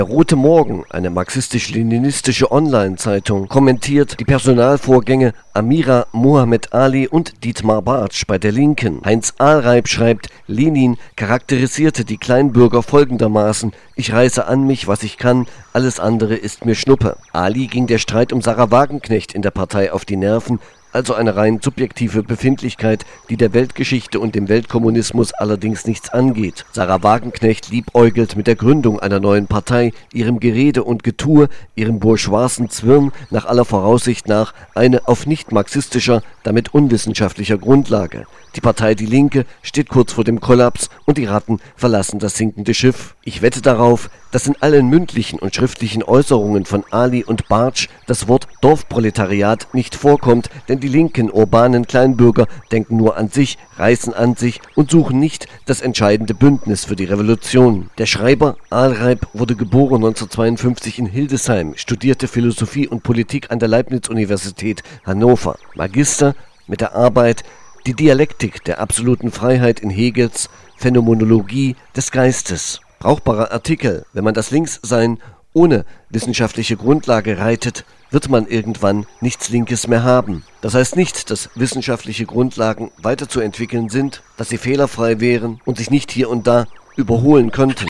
radio_interview___die_linke_roter_morgen_.mp3